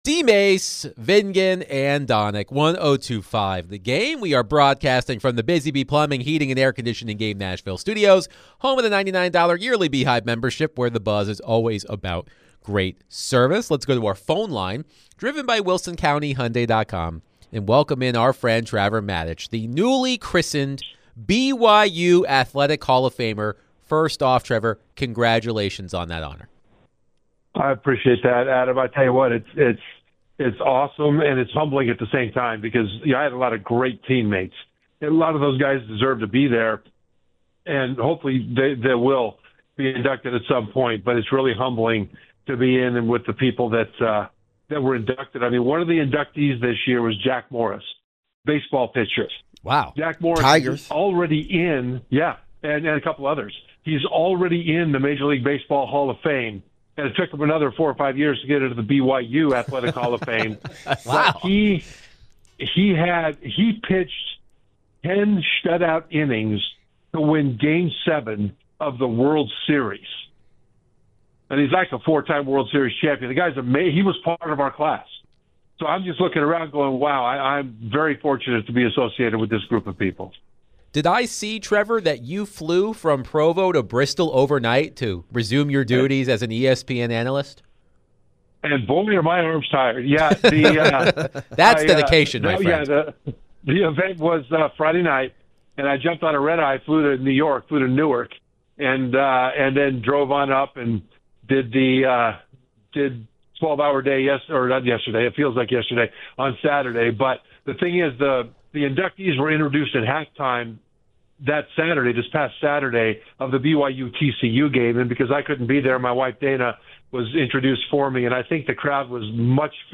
ESPN NFL and CFB Analyst Trevor Matich joins DVD to discuss Vandy, CFP Ranking, and more.